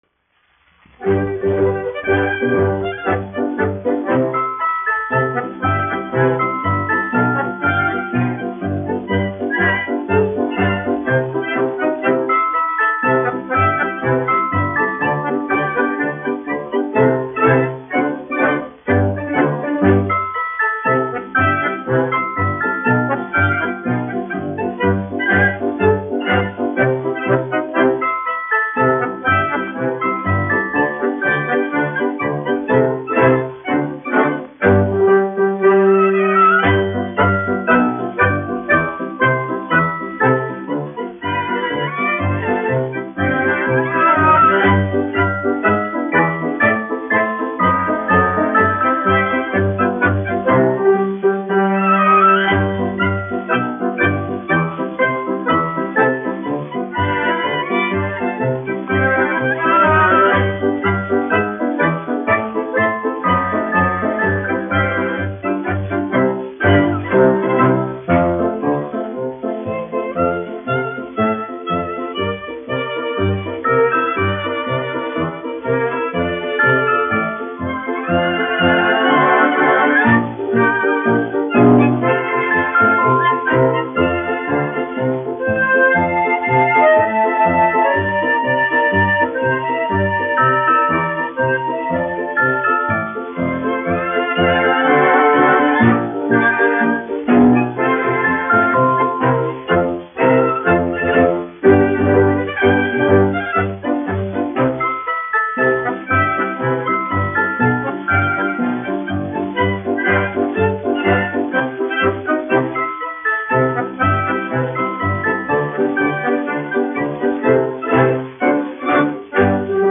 1 skpl. : analogs, 78 apgr/min, mono ; 25 cm
Populārā instrumentālā mūzika
Marši
Skaņuplate